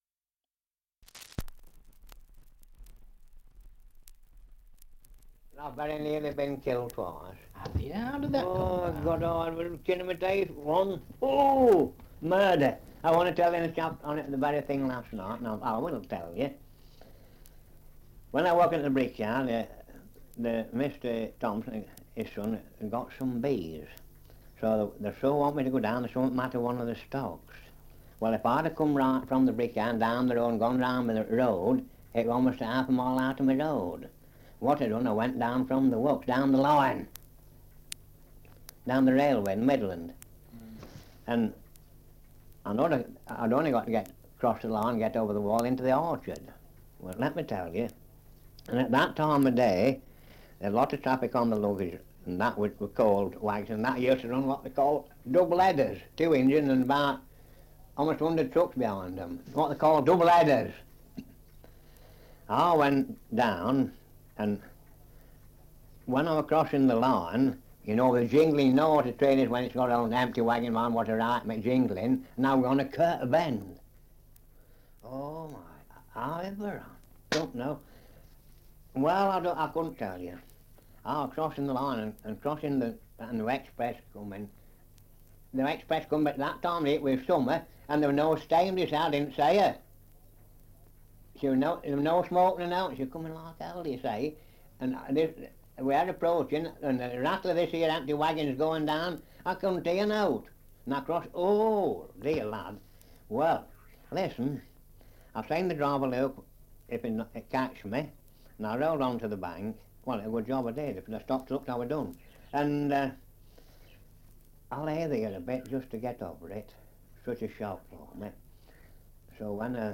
Survey of English Dialects recording in Hathern, Leicestershire
78 r.p.m., cellulose nitrate on aluminium